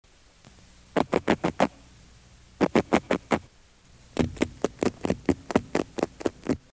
Type of sound produced grunts
Sound mechanism pharyngeal teeth stridulation amplified by adjacent swim bladder (similar to Haemulon album)
Behavioural context under duress (manual stimulation), sound production not easily provoked
Remark recordings of two specimens